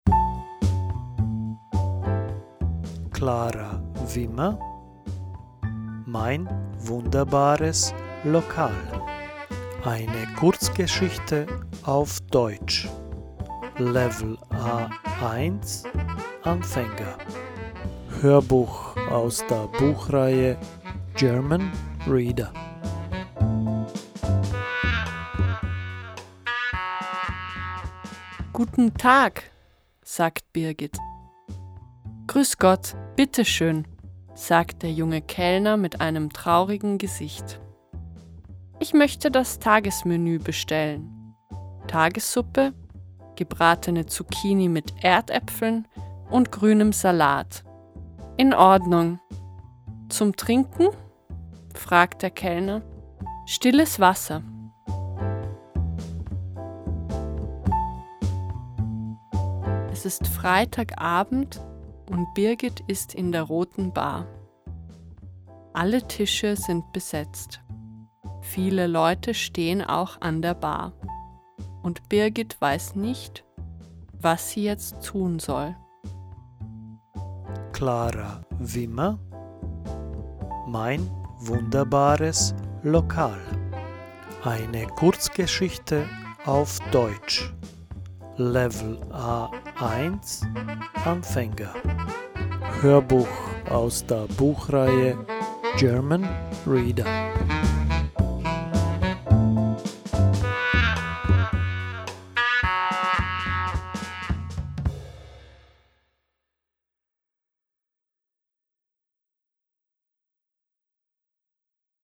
Audiobook
A1 - Beginners = Novice High